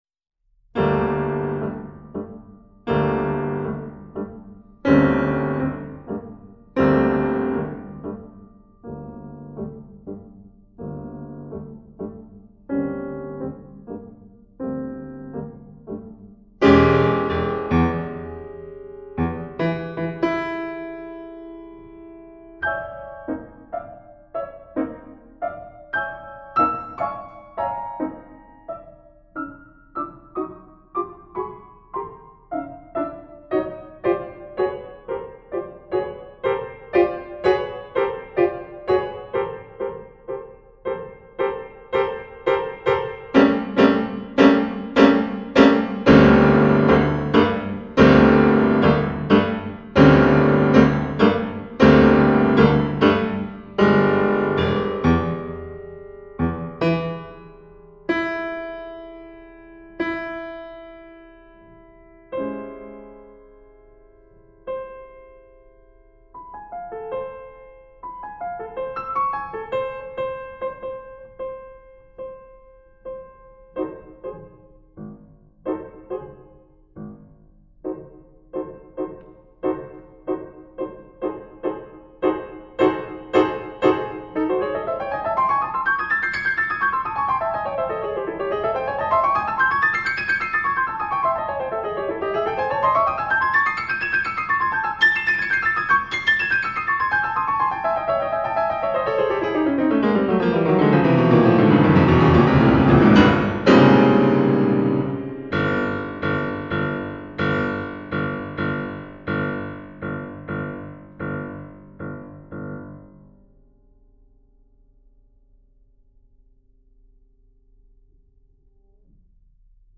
concert pianist